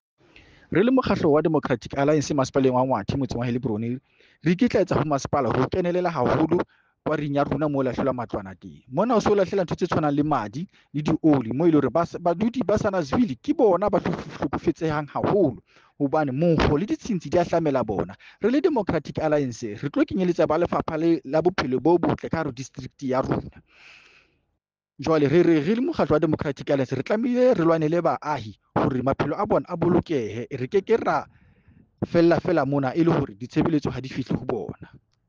Sesotho soundbite by Cllr Joseph Mbele.